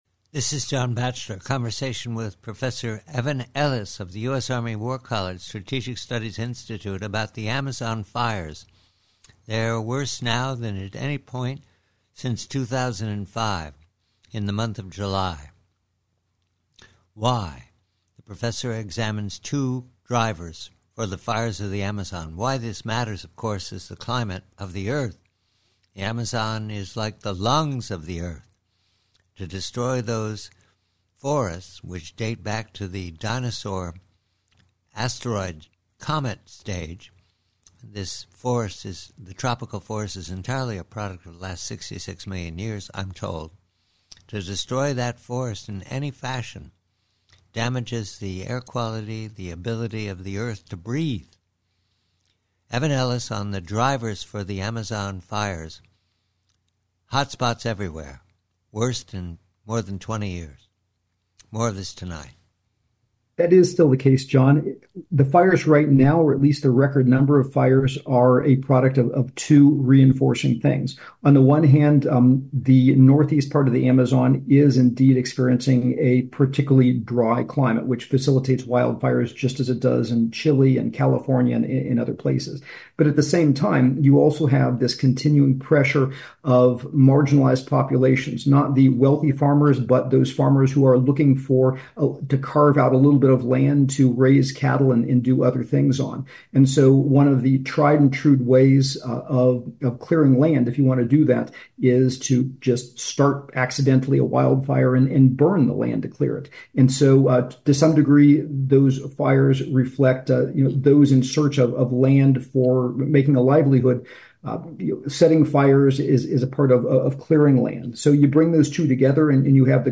PREVIEW: AMAZON: FIRES: Conversation